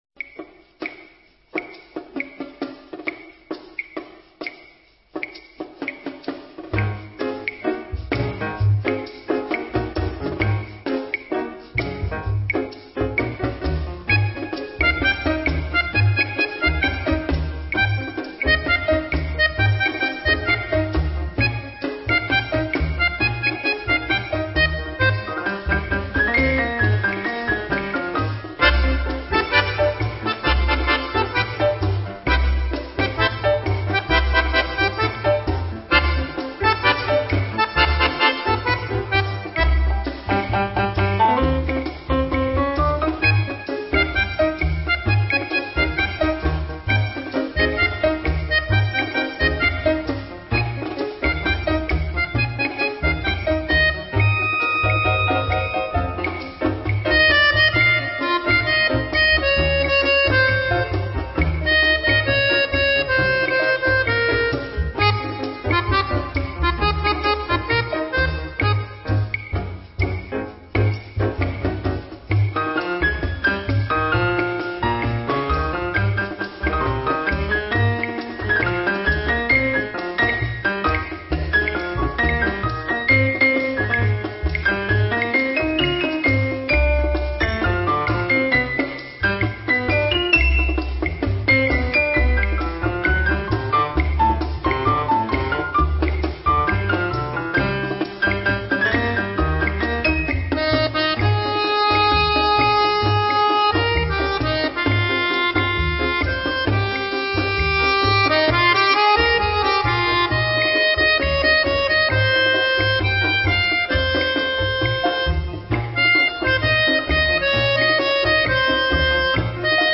Румба
со старой грампластинки